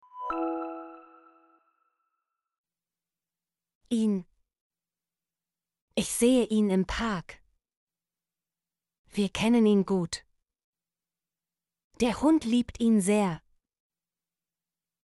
ihn - Example Sentences & Pronunciation, German Frequency List